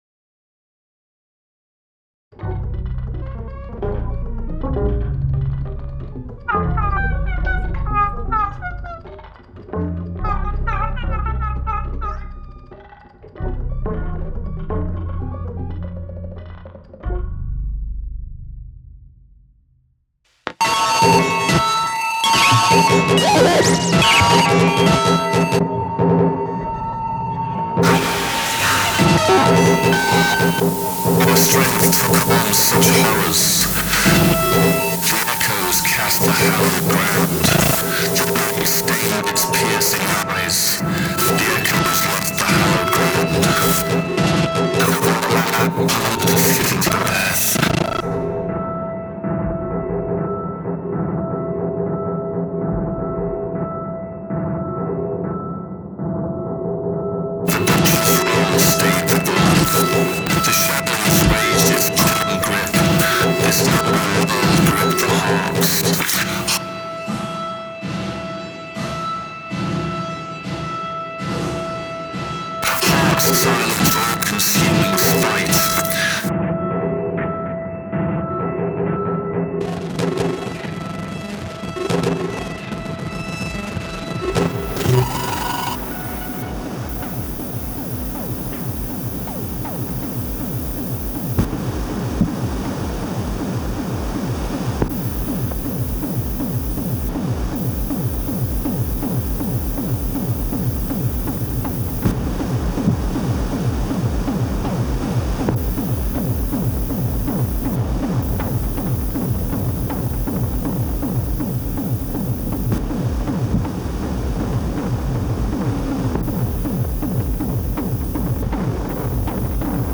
To make a fair comparison, I’ve made several different renders of a section of my current composition, composed in 5th-order ambisonics.
It also has sounds from all around and above the listener (but generally not below).
As the target in all cases would be to render something that works on both speakers and headphones, or at least one that works on speakers and another aimed at headphones, I made a ‘straight’ SAD render that doesn’t use the binauralisation of SAD’s mix plugin, and one that uses a binaural room simulation (Lunar Studio 2) that seemed right for this kind of music.
lusted-fleeting-demo8-sad-stereo.wav